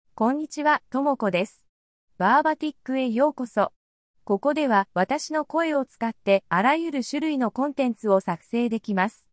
Tomoko — Female Japanese AI voice
Tomoko is a female AI voice for Japanese.
Voice sample
Female
Tomoko delivers clear pronunciation with authentic Japanese intonation, making your content sound professionally produced.